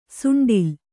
♪ suṇḍike